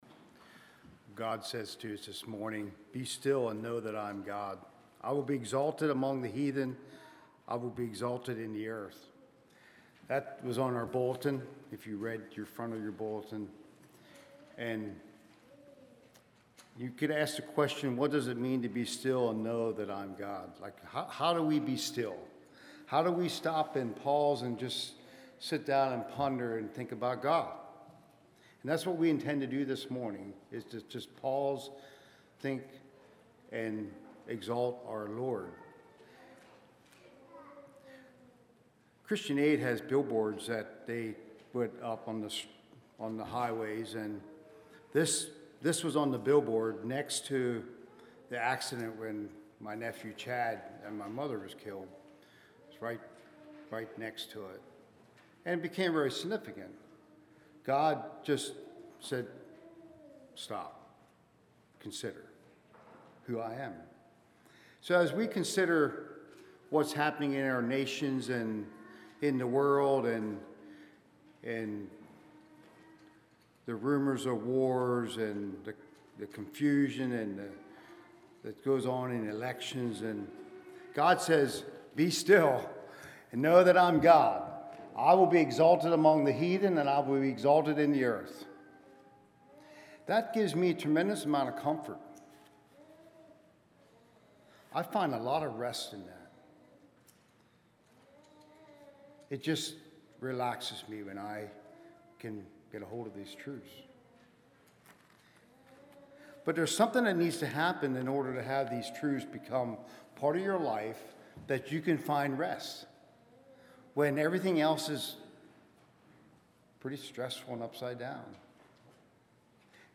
Play Now Download to Device What Is Faith and How to Obtain It Congregation: Swatara Speaker